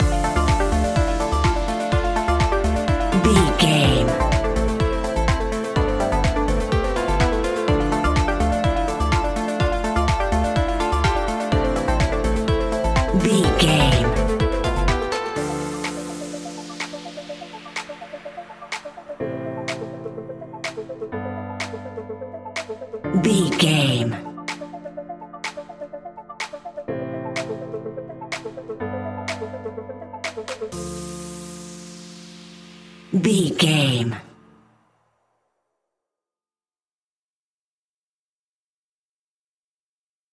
Aeolian/Minor
D
driving
energetic
uplifting
hypnotic
funky
groovy
drum machine
synthesiser
electro house
instrumentals
synth leads
synth bass